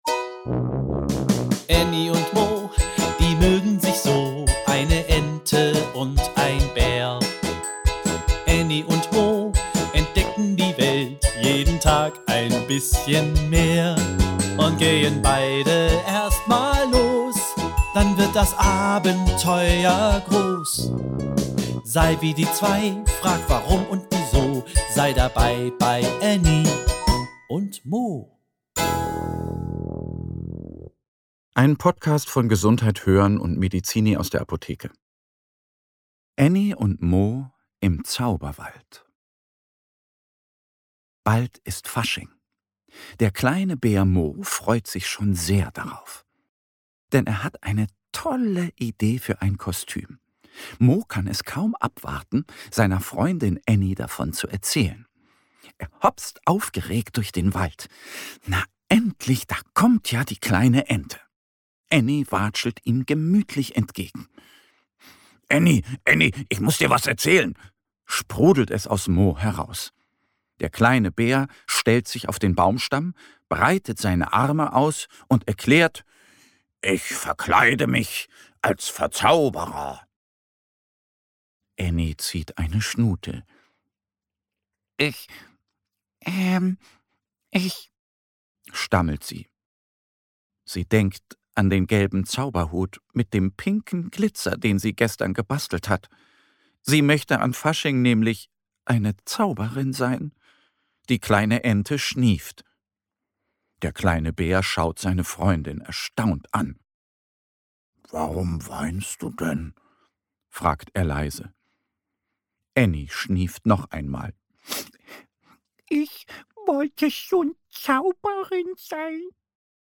Alle zwei Wochen gibt es hier eine neue Hörgeschichte, für Kinder zwischen 2 und 5 Jahren.